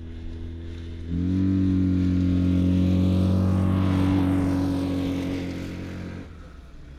Internal Combustion Subjective Noise Event Audio File (WAV)